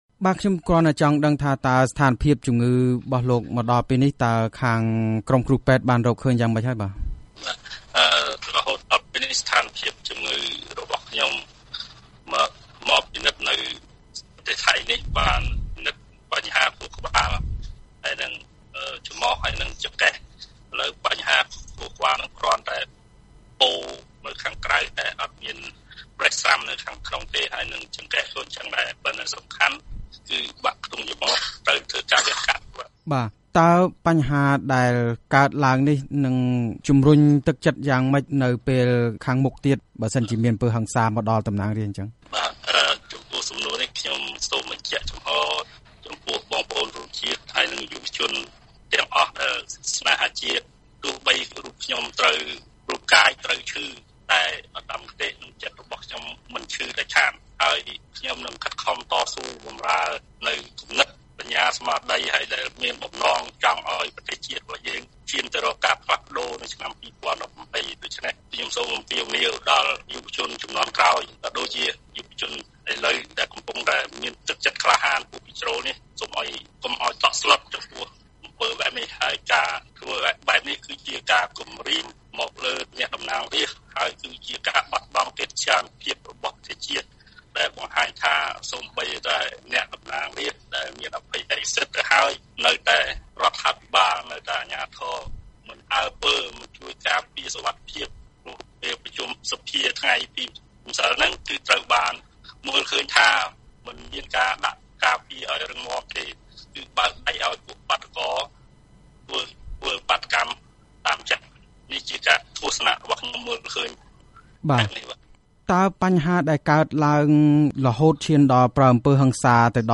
អ្នកតំណាងរាស្ត្រគណបក្សសង្គ្រោះជាតិគឺលោកគង់ សភានិងលោកញ៉យ ចំរើន  ដែលរងការវាយទាត់ធាក់ពីមនុស្សមួយក្រុមកាលពីរសៀលថ្ងៃច័ន្ទនោះកំពុងសម្រាកព្យាបាលរបួសនៅរាជធានីបាងកក ប្រទេសថៃ។ លោកទាំងពីរបានចេញដំណើរទៅព្យាបាលនៅមន្ទីរពេទ្យ Phyathai កាលពីព្រឹកថ្ងៃអង្គារនេះ។ ពេលនេះ លោកទាំងពីរនិយាយថា លោកមានរបួសបាក់ខ្ទង់ចម្រុះ របួសក្បាល បាក់ជើង ឈឺទ្រូង ដែលនាំឲ្យលោកនិយាយមិនបានច្បាស់។ លោកទាំងពីរបានអះអាងថា រឿងអំពើហិង្សាកើតឡើងនេះទំនងជារឿងនយោបាយ ហើយលោកទាំងពីរបានអំពាវនាវឲ្យយុវជនស្នេហាជាតិមានភាពក្លាហាន តស៊ូឲ្យមានការ ផ្លាស់ប្តូរសង្គមកម្ពុជានិងអំពាវនាវឲ្យអ្នកនយោបាយយកប្រាជ្ញាដោះស្រាយបញ្ហាប្រទេសជាជាងប្រើអំពើហិង្សា។ សូមស្តាប់កិច្ចសម្ភាសន៍នេះដូចតទៅ៖
បទសម្ភាសន៍ជាមួយលោកគង់ សភា តំណាងរាស្ត្រគណបក្សសង្គ្រោះជាតិដែលរងរបួស